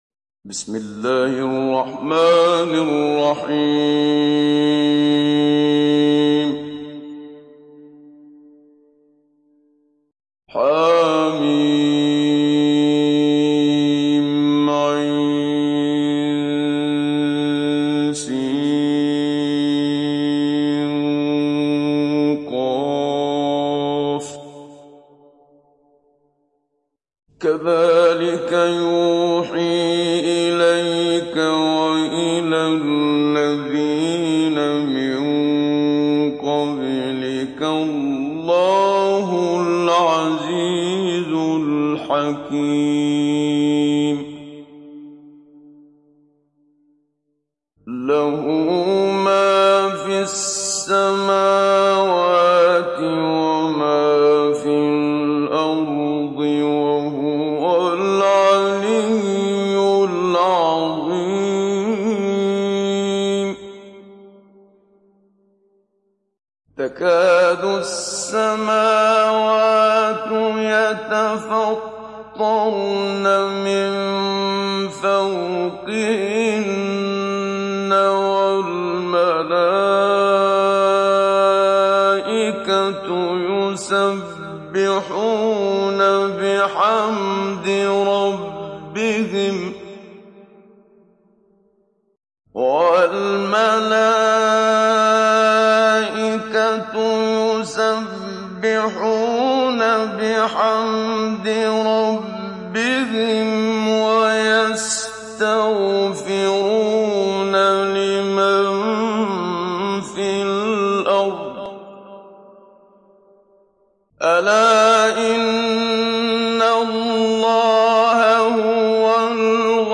تحميل سورة الشورى محمد صديق المنشاوي مجود